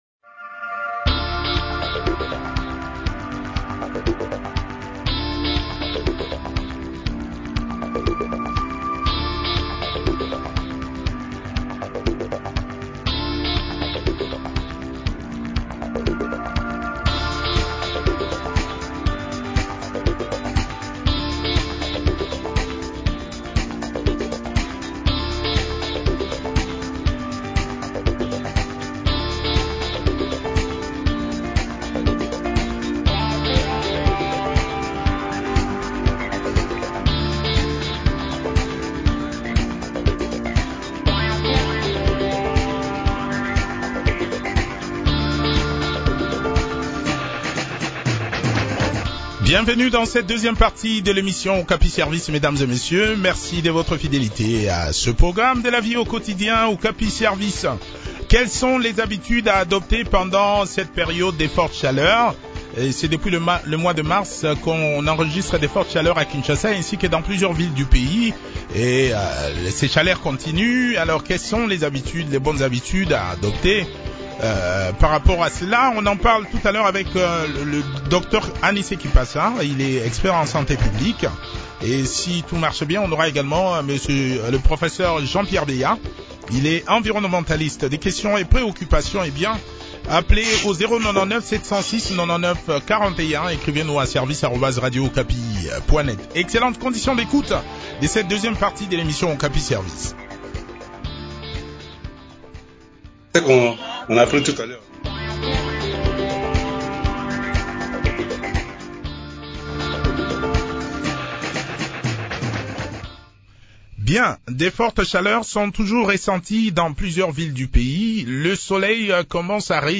expert en santé publique a également pris part à cette interview.